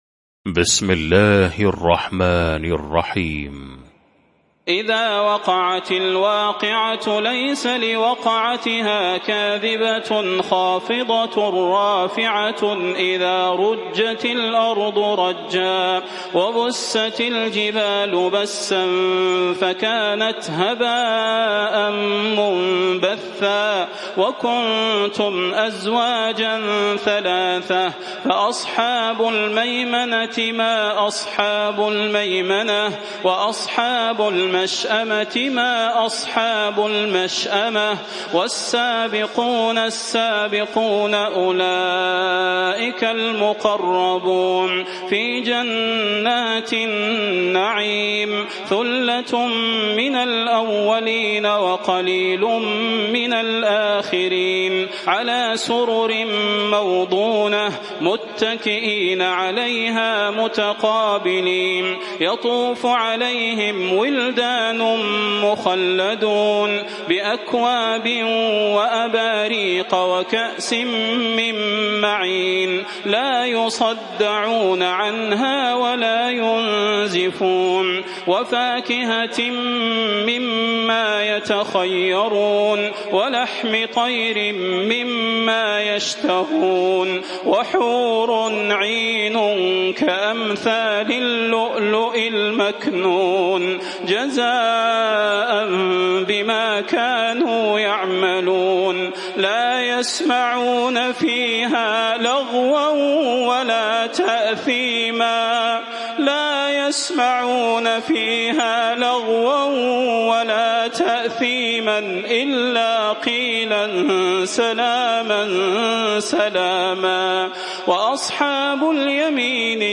المكان: المسجد النبوي الشيخ: فضيلة الشيخ د. صلاح بن محمد البدير فضيلة الشيخ د. صلاح بن محمد البدير الواقعة